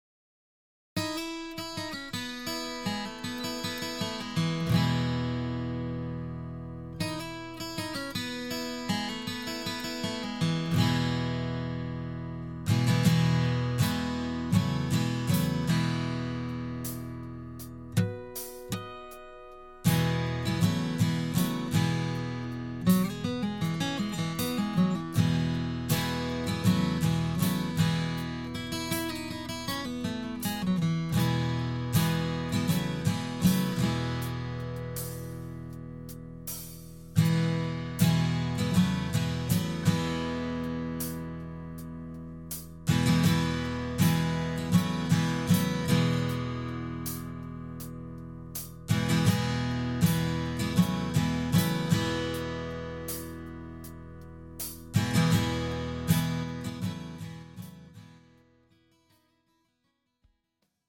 MR 반주입니다.